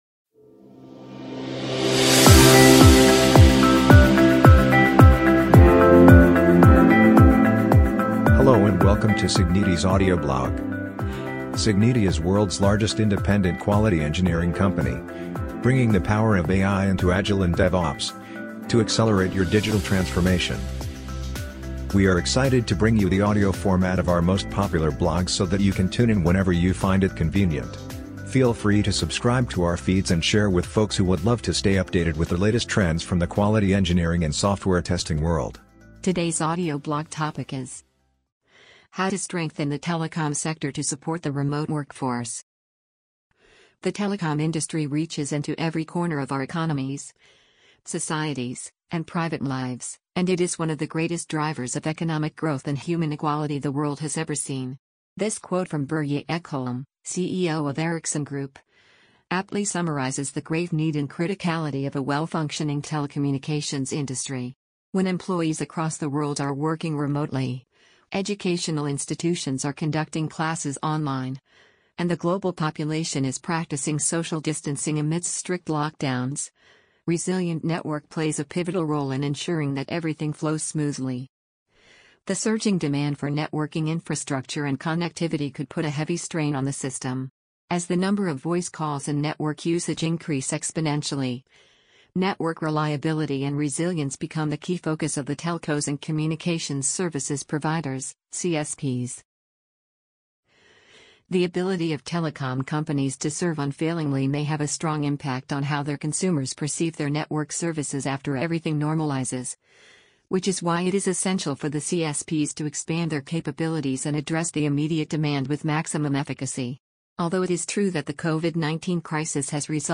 amazon_polly_14236.mp3